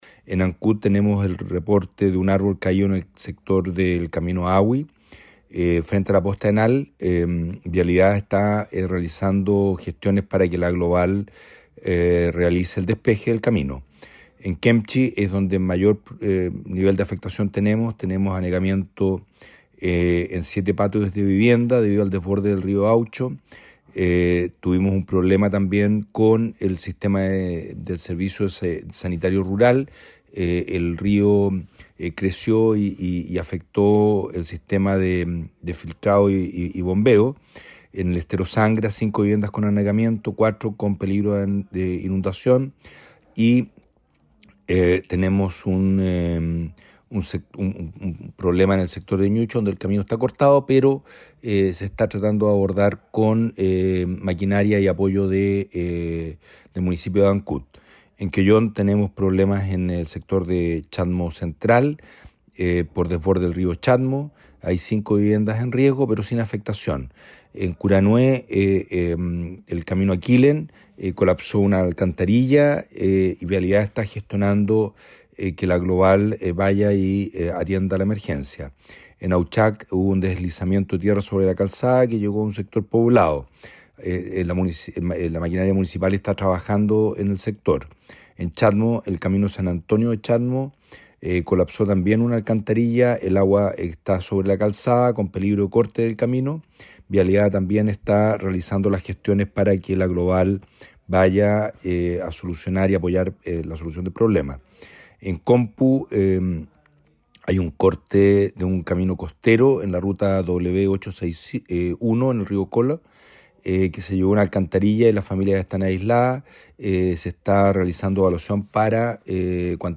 Así también se reportó de consecuencias por el viento y la lluvia en Ancud, Quellón y Quemchi, añadió Marcelo Malagueño.